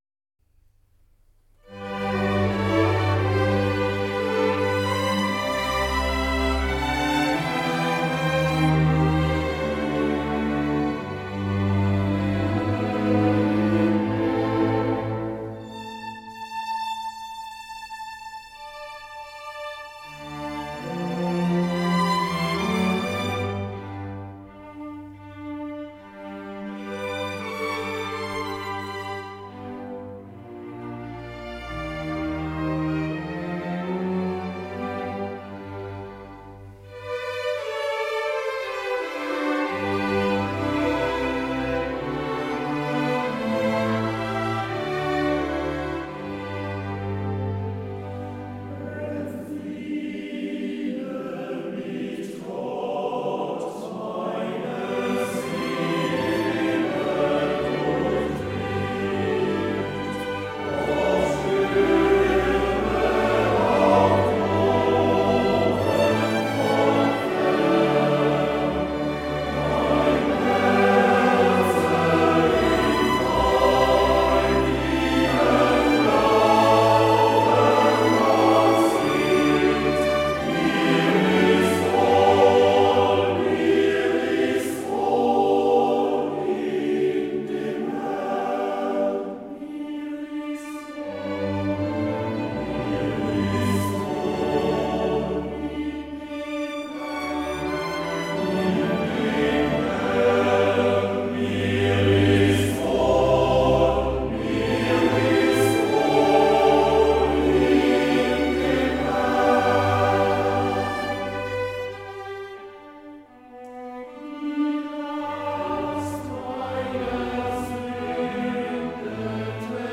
cantus firmus-Sätze + kleine geistliche Stücke (Auswahl)
(Männerchor, Streichorchester)